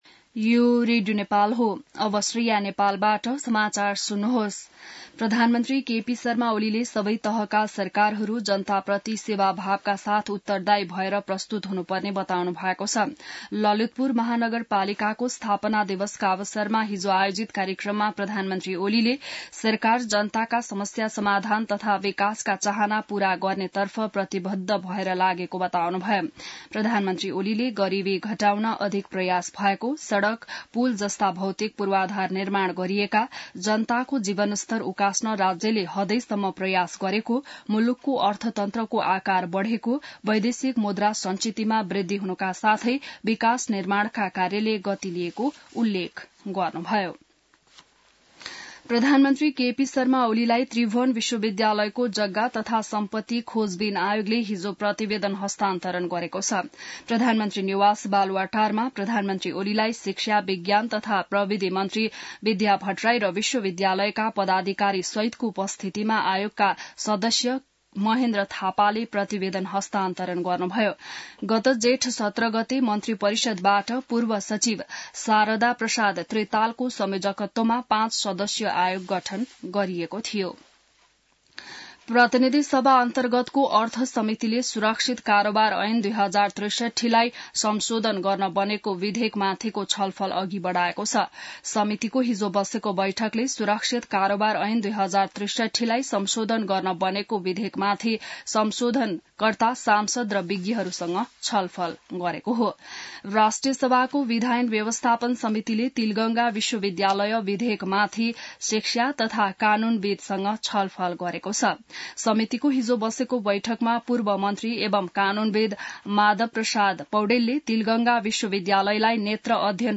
बिहान ६ बजेको नेपाली समाचार : ४ पुष , २०८१